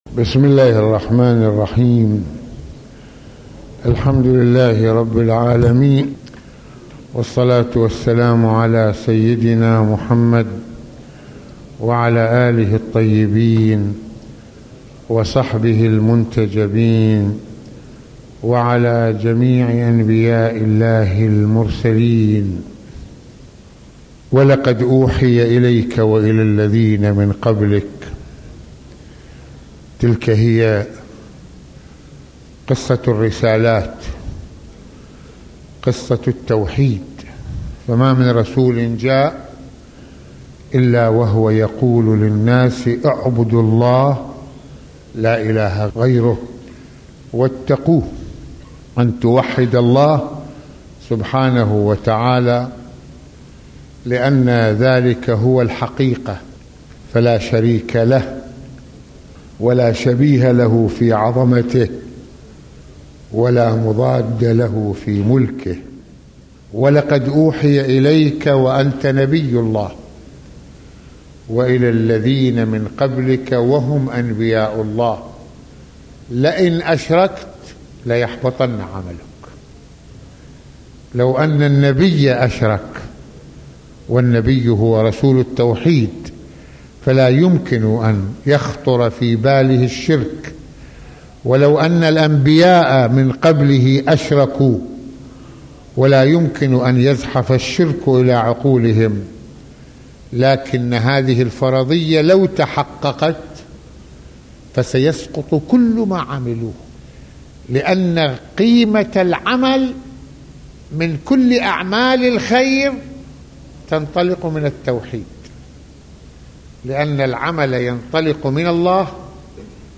- المناسبة : موعظة ليلة الجمعة المكان : مسجد الإمامين الحسنين (ع) المدة : 22د | 16ث المواضيع : توحيد الله اساس الرسالات - تربية عظمة الله تعالى في نفوسنا - من مشاهد يوم القيامة - اجر المؤمنين في الجنة.